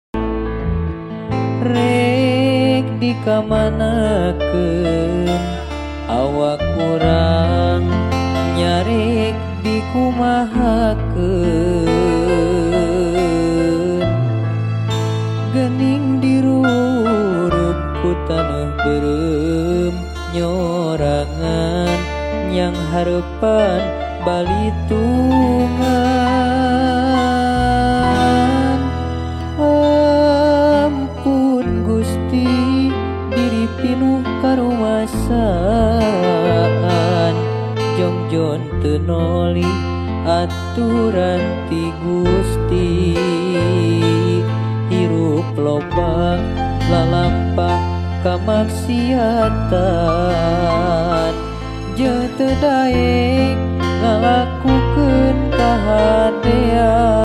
LIVE ACOUSTIC COVER
Gitar
Keyboard